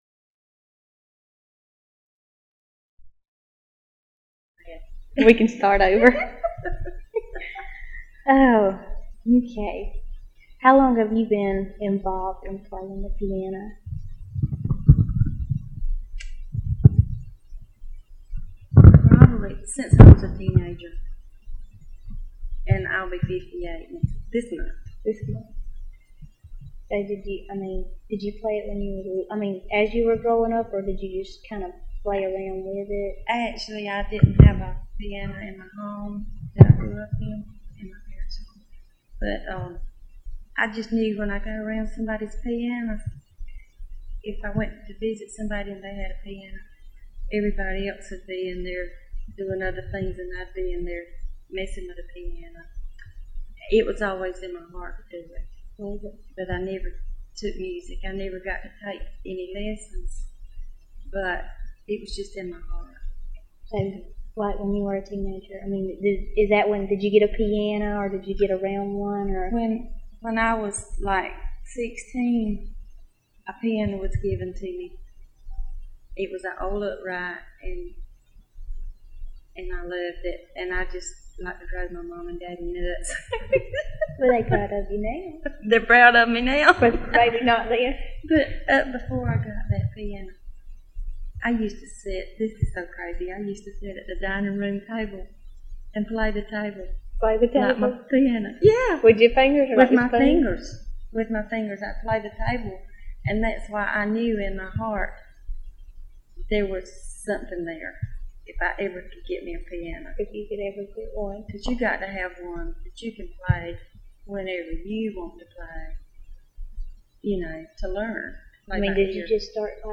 Audio file from cassette tape. Part of the South Georgia Folklife Project at Valdosta State University Archives and Special Collections. Topics includes gospel music.